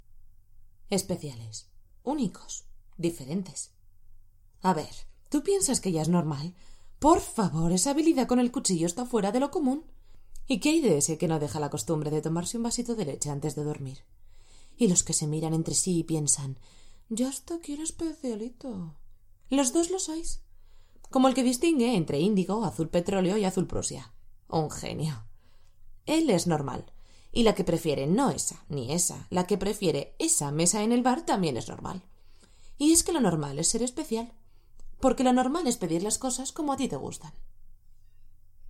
Voz de un rango entre los 15 años y los 55. Posibilidad de hacer niños y niñas pequeños. Puedo hacer desde un anuncio con voz sexy y cálida hasta algo muy rápido y completamente cantado. Puedo poner voz clásica de locutora o salir de esos registros y anunciarte todo con un tono de calle, mas neutral. Realizo también presentaciones y todo lo que se te ocurra! _____________________________________________________________ Voice of a range between 15 years and 55. Possibility of making small children. I can do from an ad with a sexy and warm voice to something very fast and completely sung. I can put the classic voice of announcer or leave those records and announce everything with a tone of street, more neutral. I also make presentations and everything you can think of!
Sprechprobe: Industrie (Muttersprache):
SPOT PUBLICIDAD_0.mp3